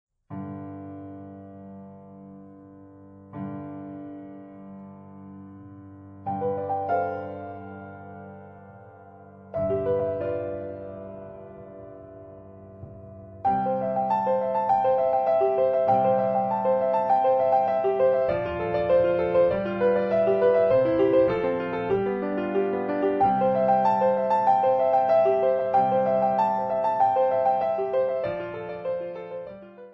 12 Klavierstücke, mittelschwer
Besetzung: Klavier